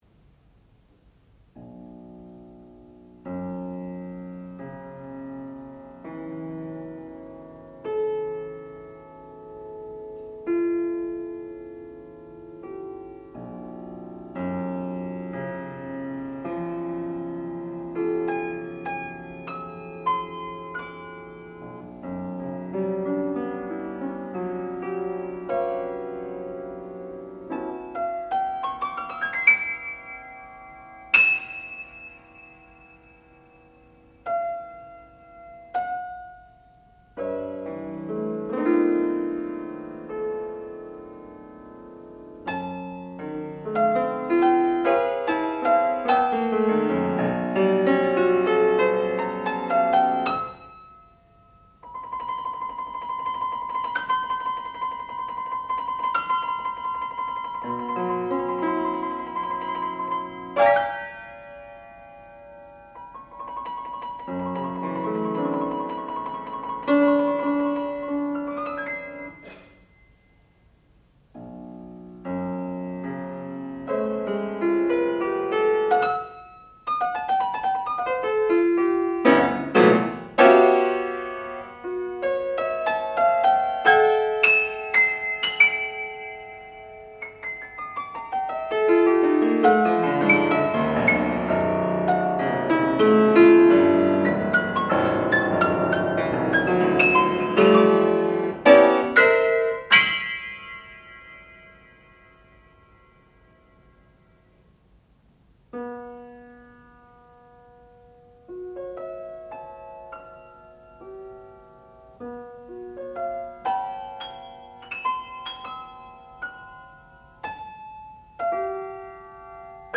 sound recording-musical
classical music
Graduate Recital